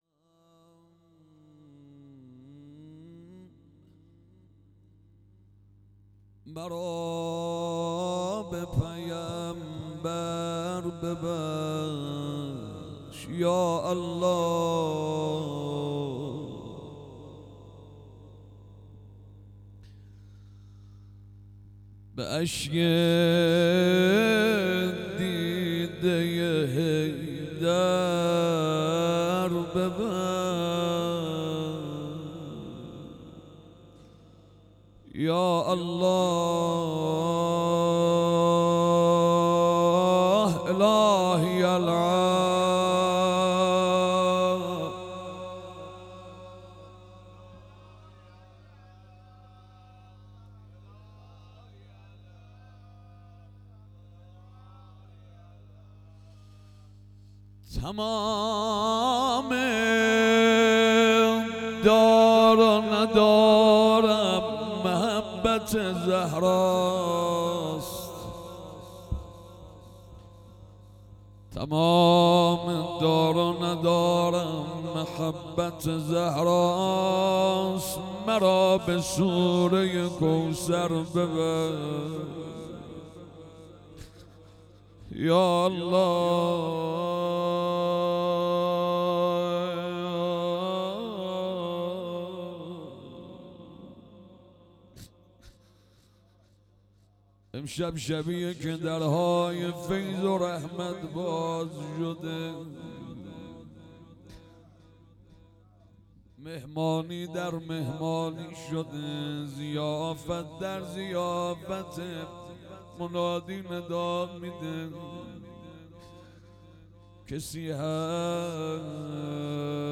هیئت حضرت ابوالفضل(ع)ورامین - شب های قدرشهادت حضرت علی(ع)مناجات